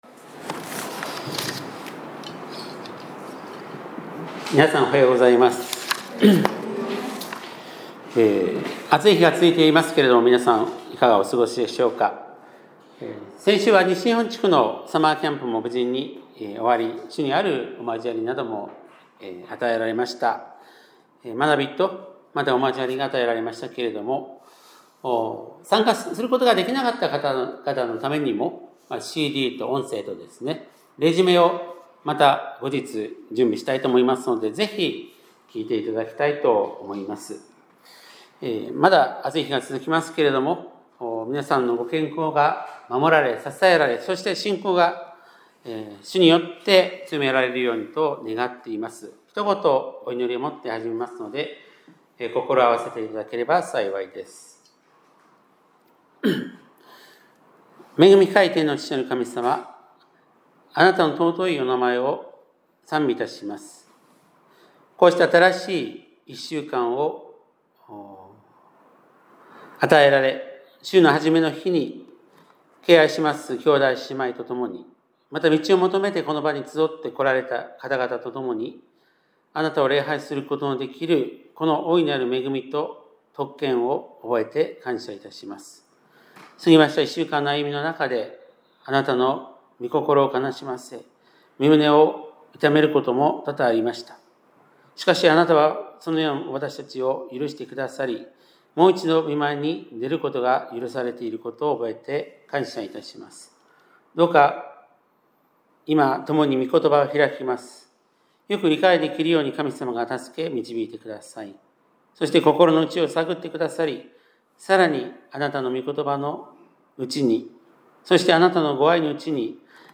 2025年8月17日（日）礼拝メッセージ